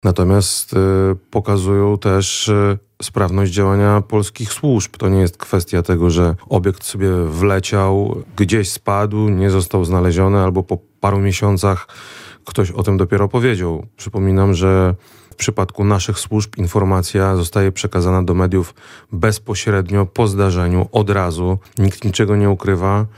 Wojewoda lubelski Krzysztof Komorski mówi, że administracja rządowa w terenie musi być przygotowana na takie incydenty jak drony wlatujące w polską przestrzeń powietrzną.